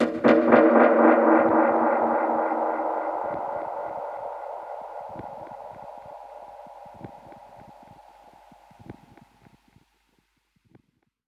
Index of /musicradar/dub-percussion-samples/85bpm
DPFX_PercHit_A_85-03.wav